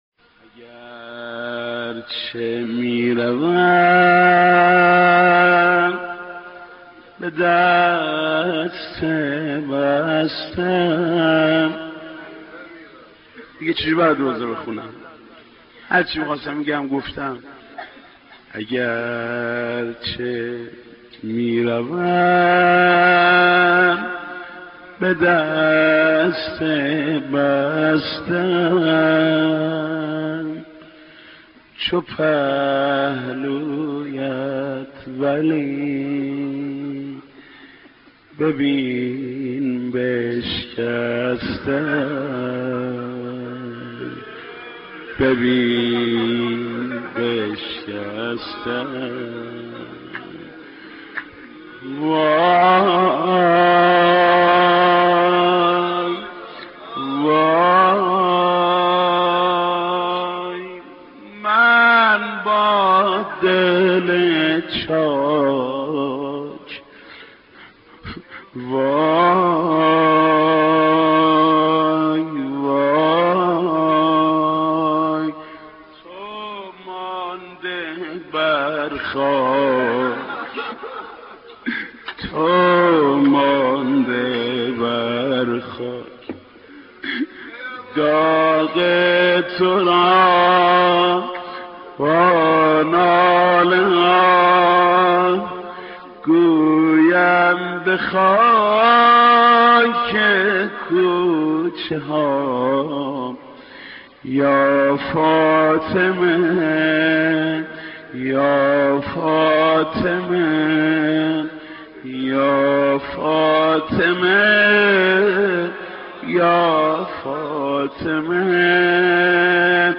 دانلود مداحی اگر چه می روم به دست بسته ام - دانلود ریمیکس و آهنگ جدید
نوحه شهادت حضرت فاطمه(س) با صدای محمود کریمی (8:15)